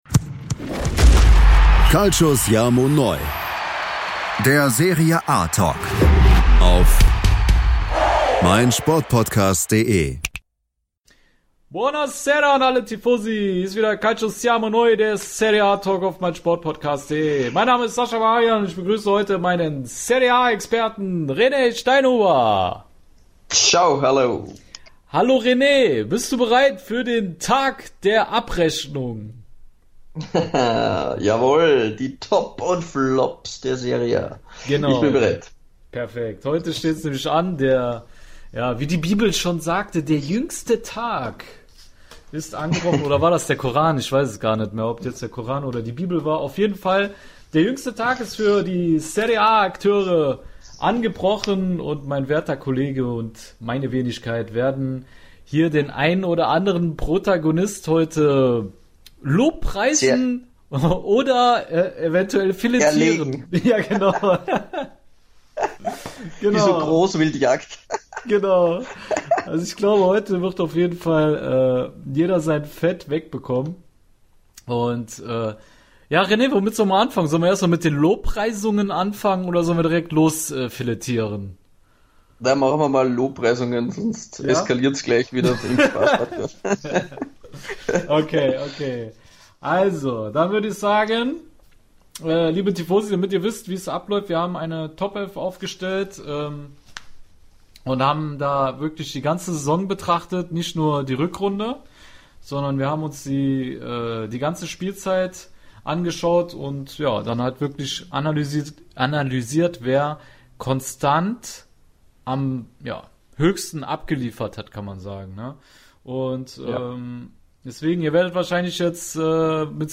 Die muntere Talkrunde